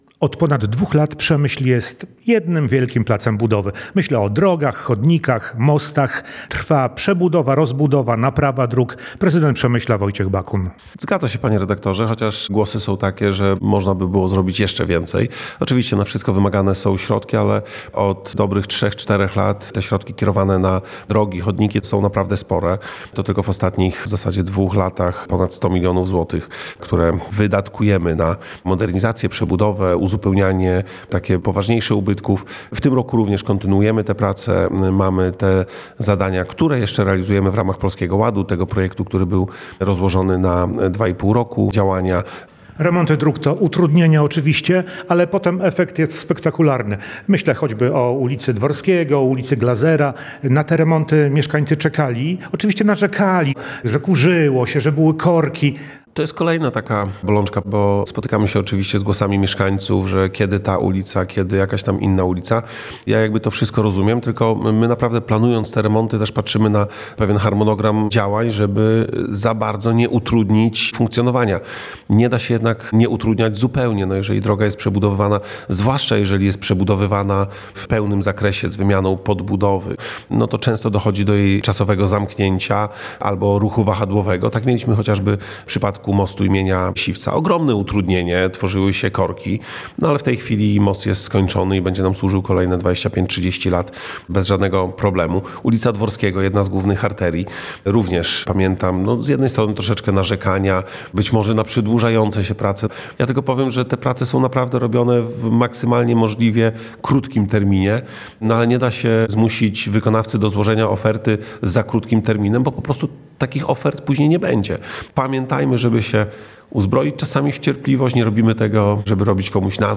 O-remontach-drog-mowi-prezydent-Przemysla-Wojciech-Bakun-1.mp3